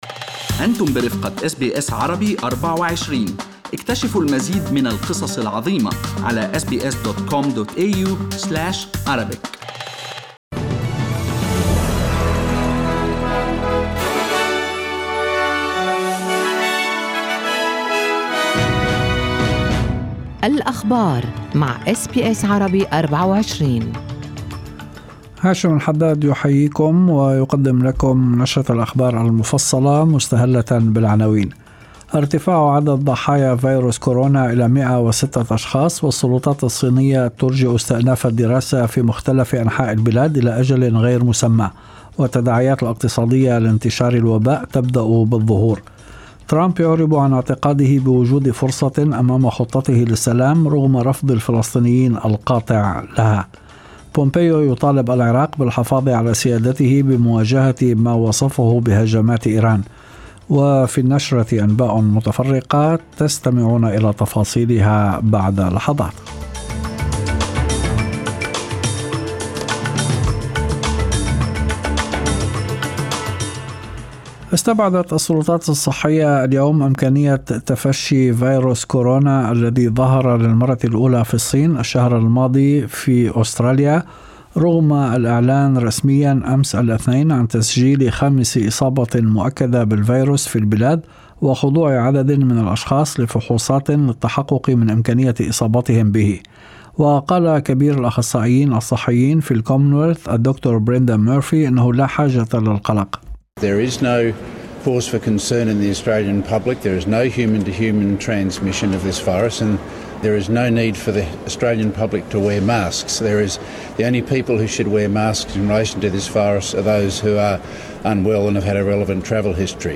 نشرة أخبار المساء 28/01/2020
Arabic News Bulletin Source: SBS Arabic24